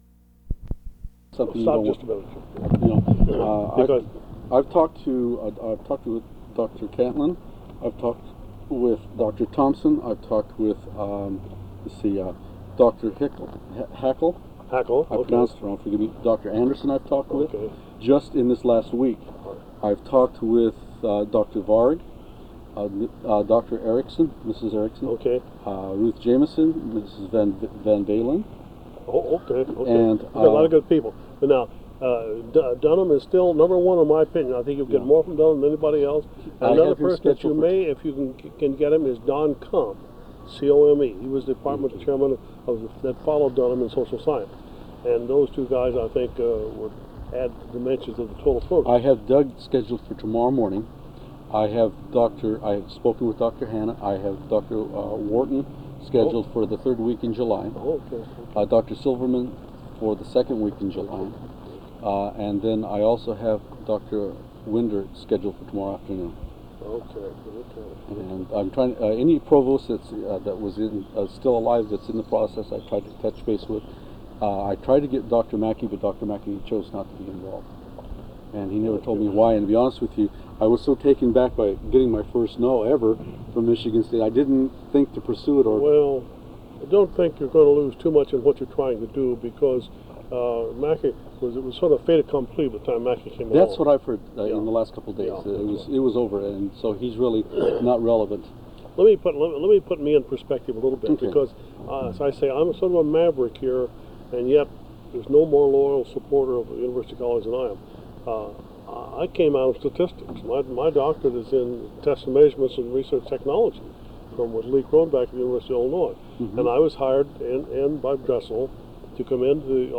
Interview
Date: June 27, 1990 Format: Audio/mp3 Original Format: Audio cassette tape Resource Identifier: A008658 Collection Number: UA 10.3.156 Language: English Rights Management: Educational use only, no other permissions given.